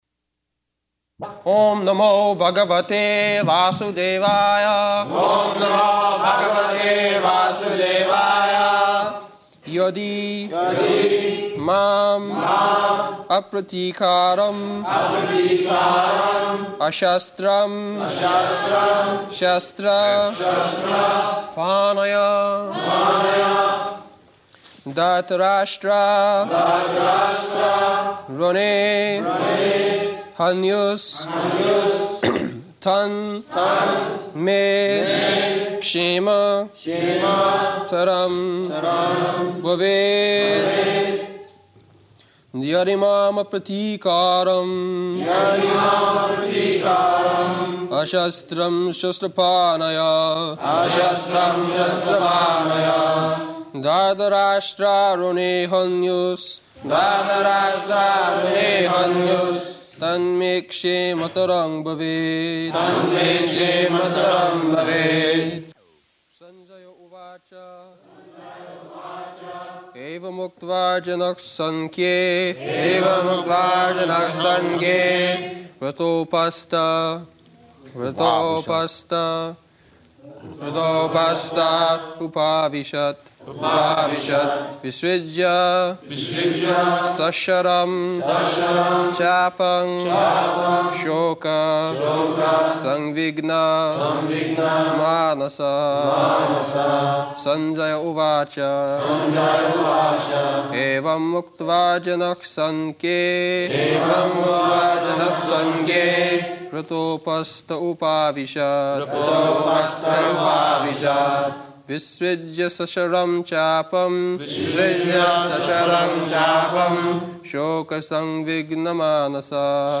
August 1st 1973 Location: London Audio file
[Prabhupāda and devotees repeat]